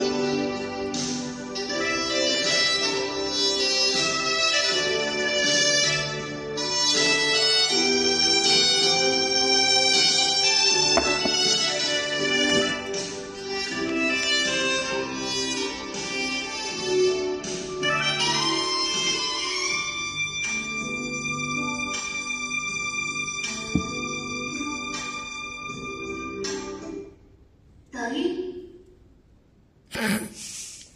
靜校鈴聲中的抖音
放了十五分鐘的音樂，三次抖音出現🤔